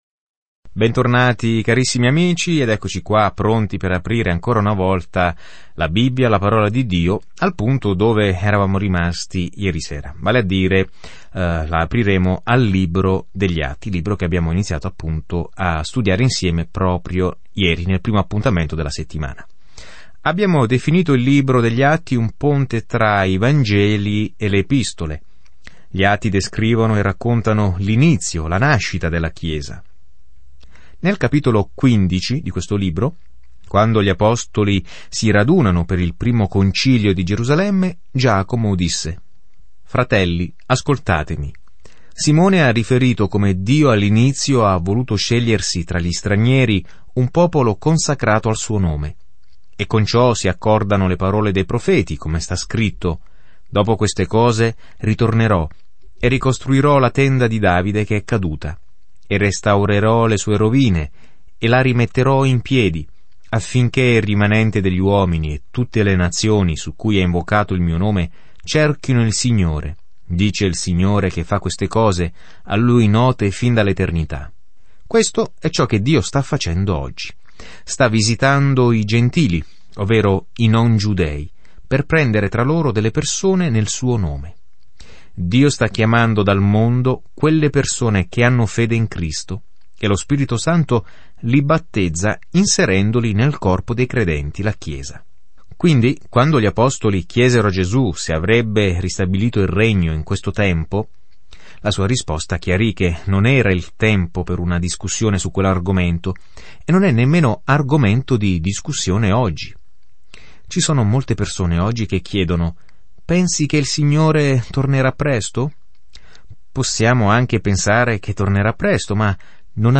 Scrittura Atti degli Apostoli 1 Atti degli Apostoli 2:1-13 Giorno 1 Inizia questo Piano Giorno 3 Riguardo questo Piano L’opera di Gesù iniziata nei Vangeli ora continua attraverso il suo Spirito, mentre la chiesa viene piantata e cresce in tutto il mondo. Viaggia ogni giorno attraverso gli Atti mentre ascolti lo studio audio e leggi vers ...